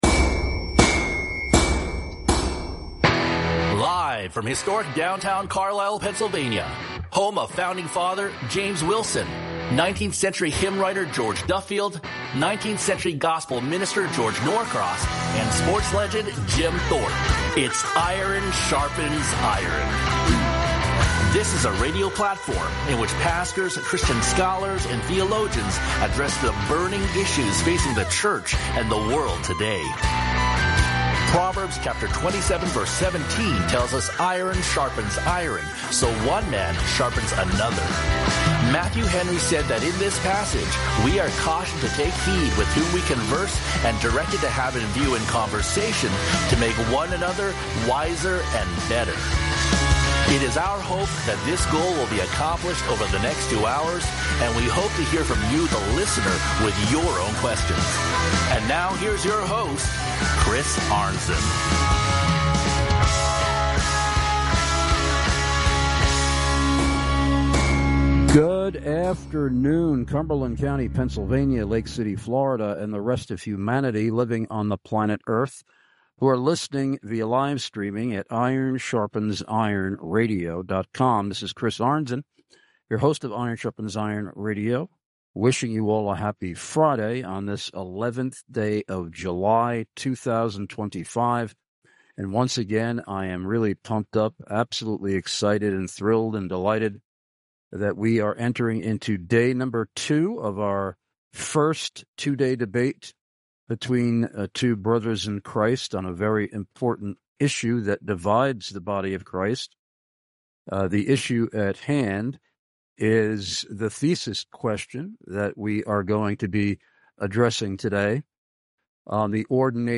THE FIRST OF TWO LIVE DEBATES in JULY!!!!!!!!